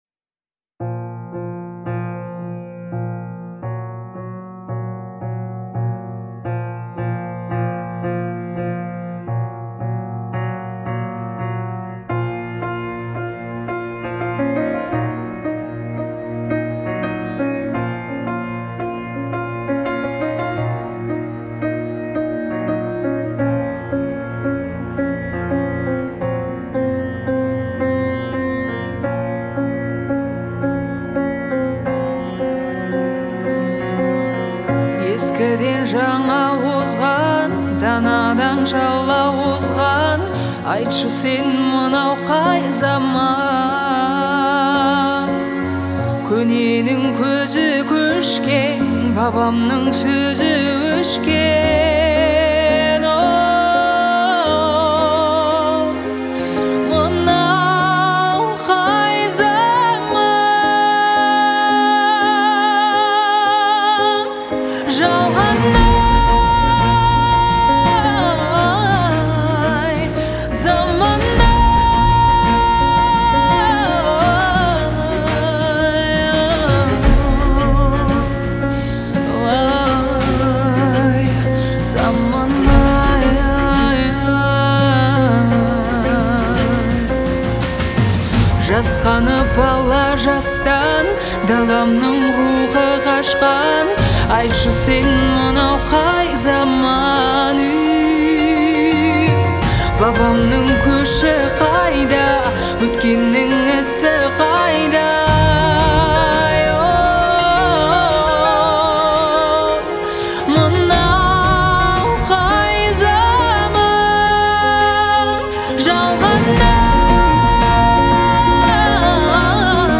это яркий пример казахского поп-фолка